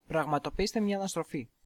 (male)